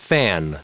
Prononciation du mot fan en anglais (fichier audio)
Prononciation du mot : fan